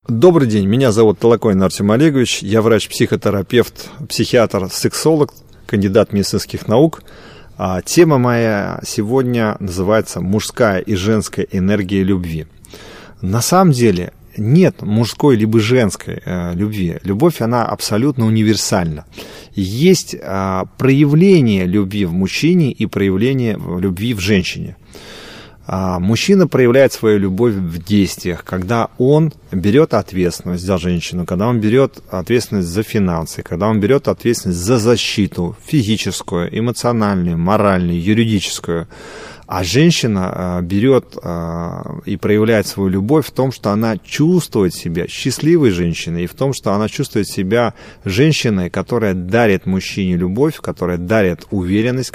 Аудиокнига Мужская и женская энергия любви | Библиотека аудиокниг